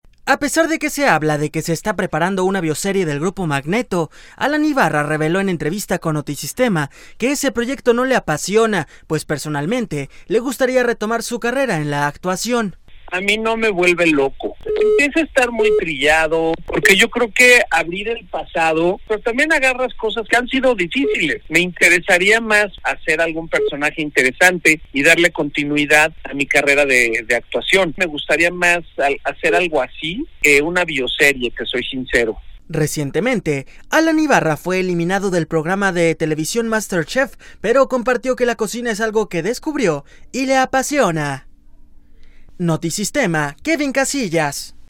A pesar de que se habla de que se está preparando una bioserie del grupo Magneto, Alan Ibarra reveló en entrevista con Notisistema que ese proyecto no le apasiona, pues personalmente le gustaría retomar su carrera en la actuación.